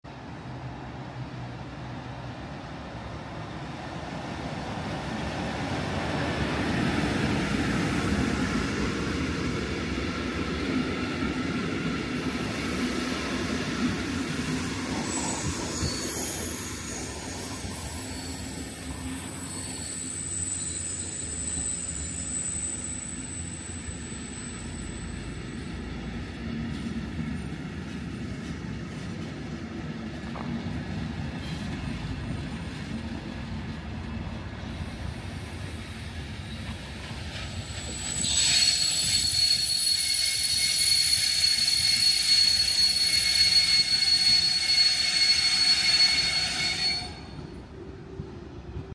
RE1 gamescom-Verstärker – und wieder hat TRI gezaubert. 👉 Baureihe 111 mit n-Wagen & ehemaligen MRB UIC-Z Wagen bei der Einfahrt in Köln Messe/Deutz. Die guten alten Klotzbremsen.